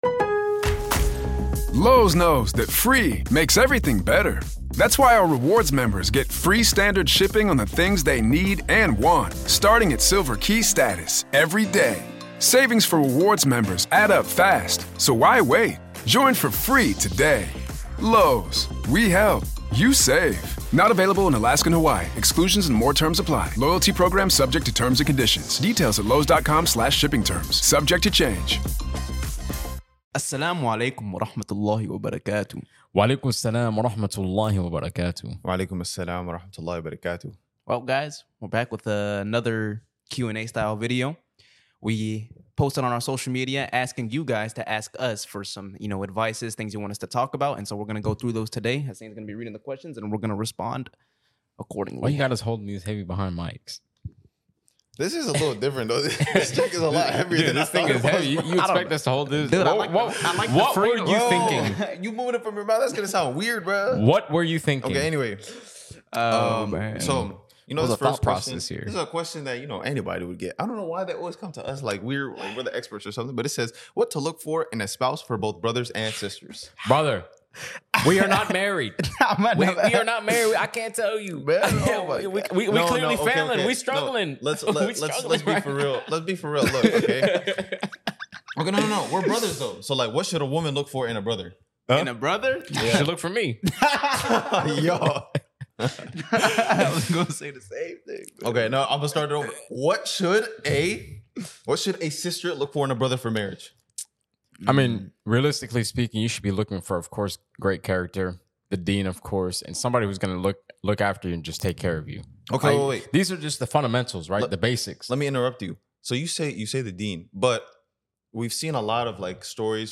DeenTour is a podcast and channel where 3 brothers showcase their love for islam through reminders, brotherhood, motivation, entertainment, and more!